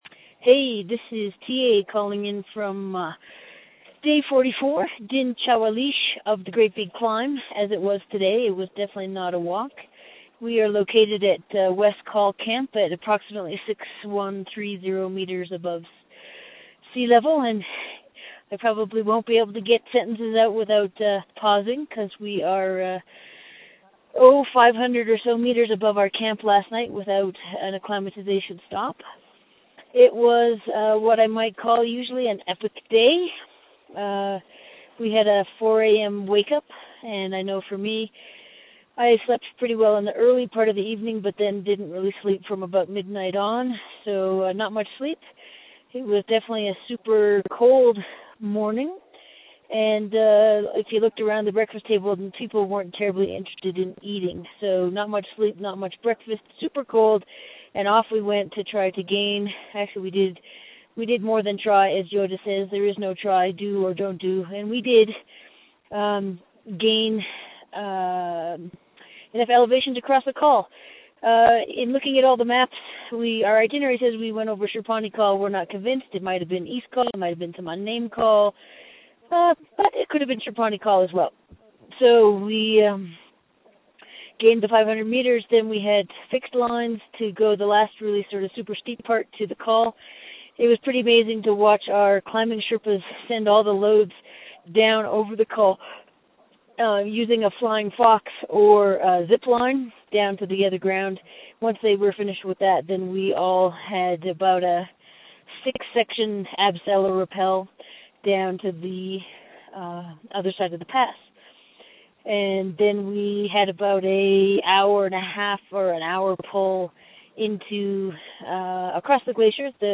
Great Big Walk acknowledges the support of the Memorial University of Newfoundland Quick Start Fund for Public Engagement in making these updates from the field possible.